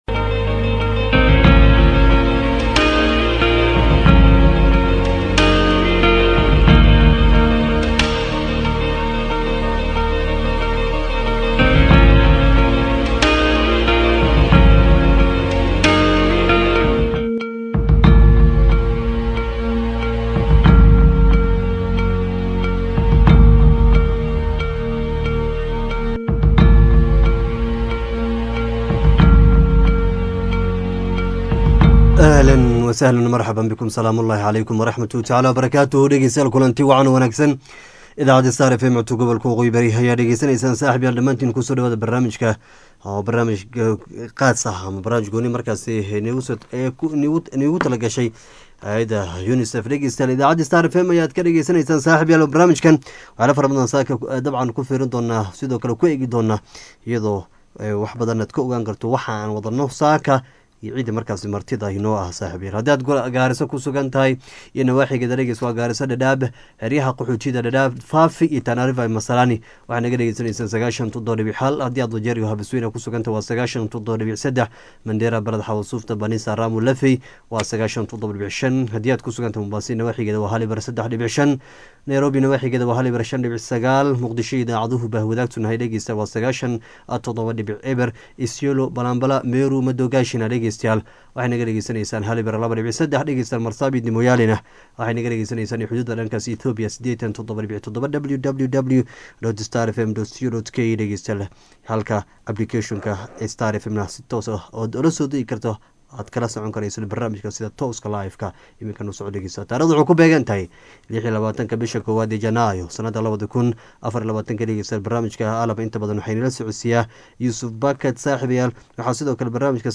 Wareysi